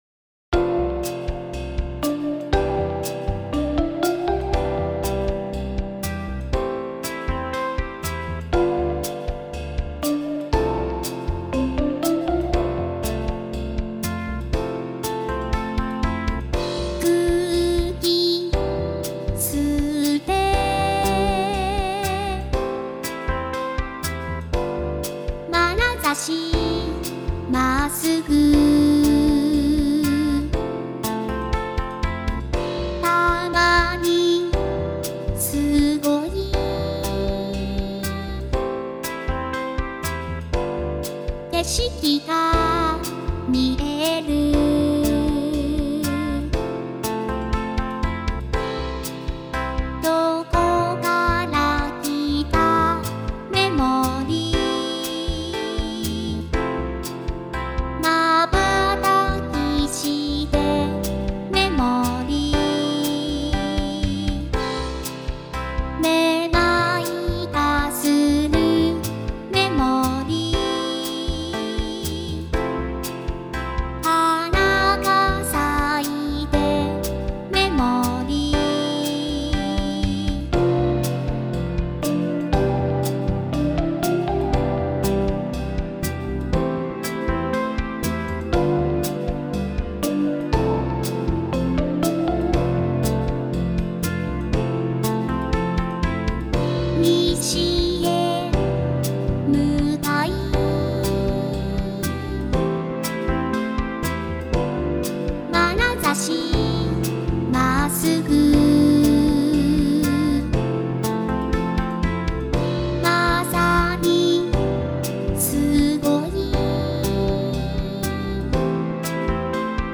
合成音声歌唱ソフトを使ってます。